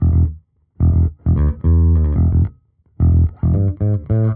Index of /musicradar/dusty-funk-samples/Bass/110bpm
DF_JaBass_110-E.wav